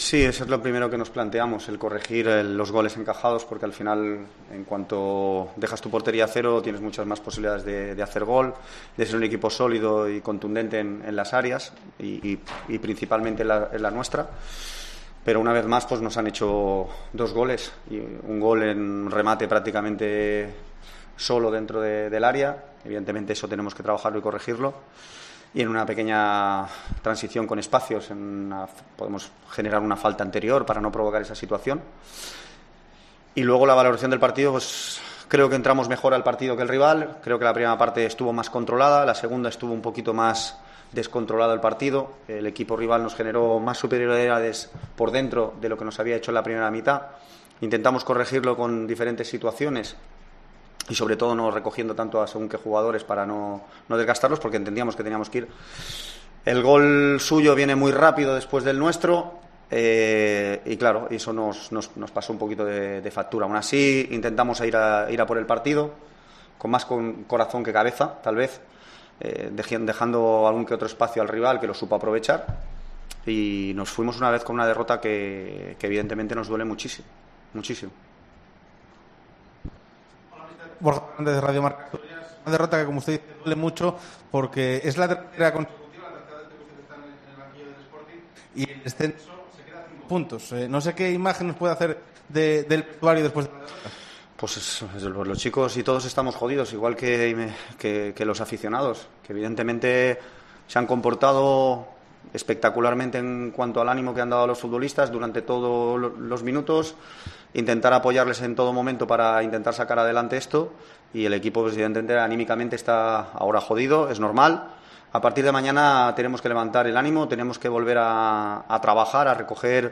AUDIO: Rueda de prensa